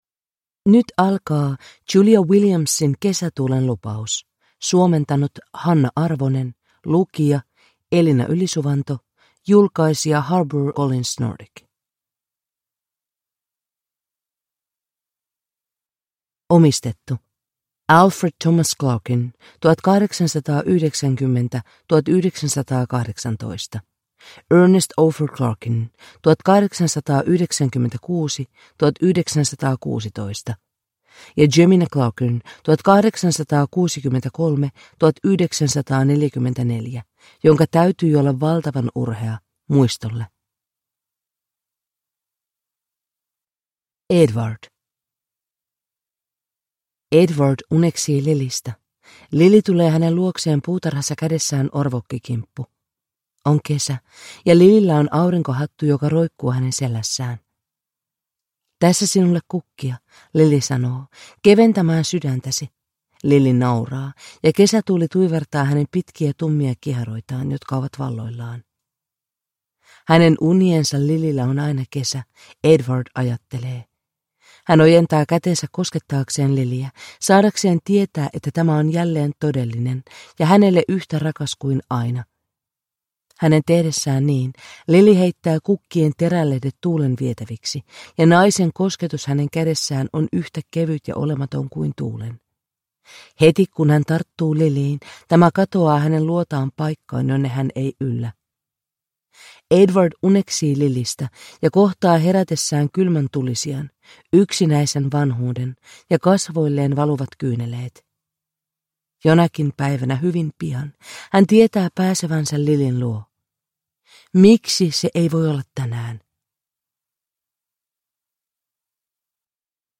Kesätuulen lupaus (ljudbok) av Julia Williams